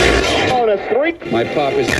120BPMRAD9-L.wav